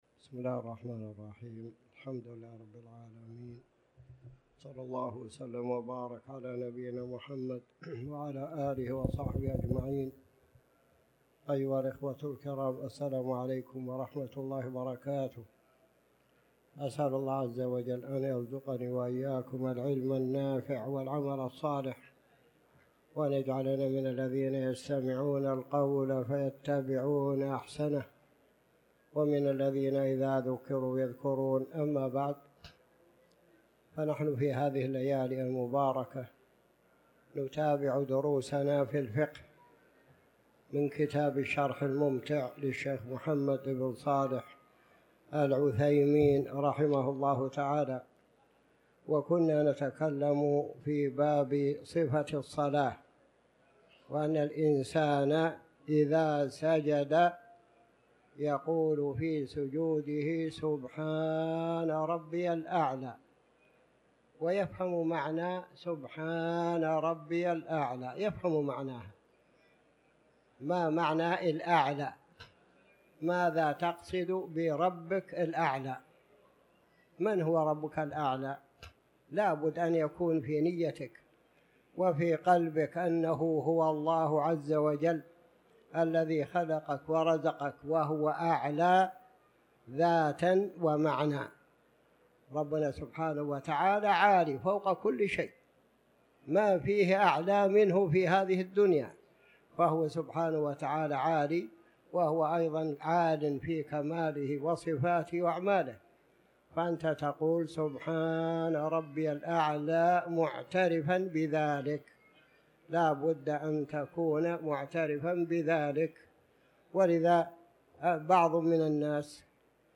تاريخ النشر ١١ ذو القعدة ١٤٤٠ هـ المكان: المسجد الحرام الشيخ